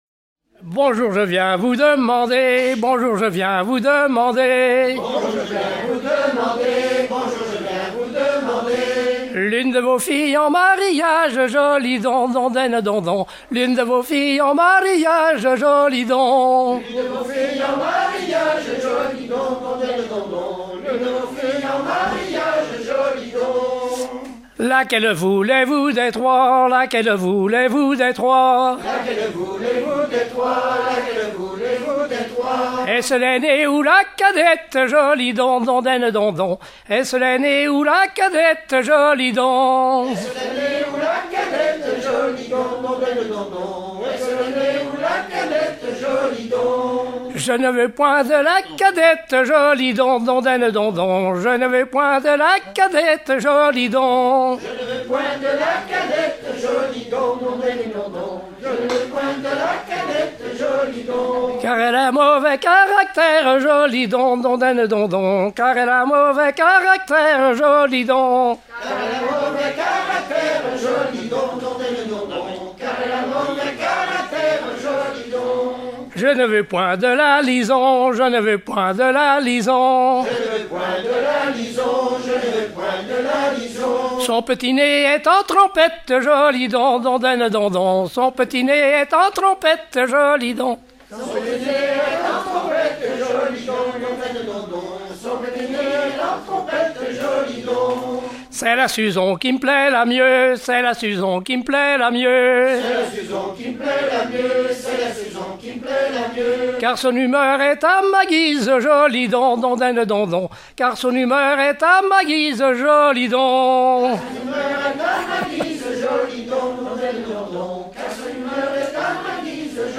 Genre laisse
Catégorie Pièce musicale éditée